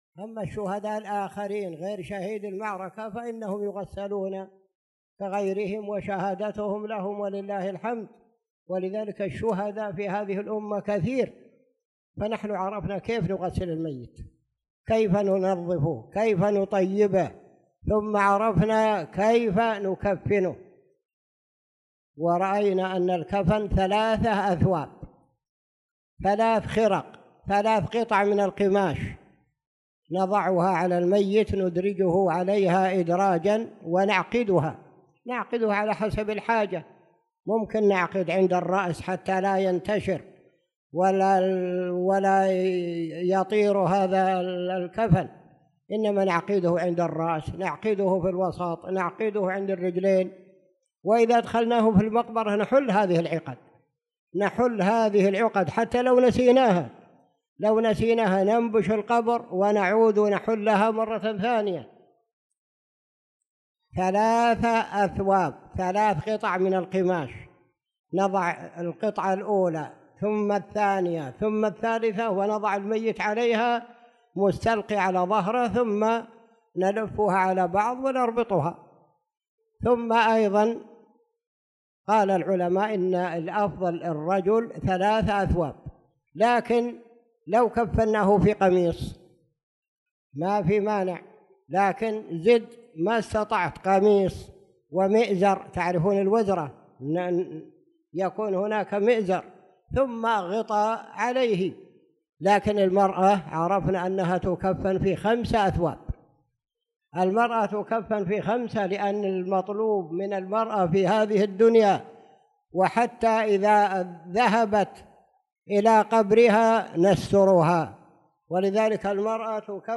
تاريخ النشر ١٠ ذو القعدة ١٤٣٧ هـ المكان: المسجد الحرام الشيخ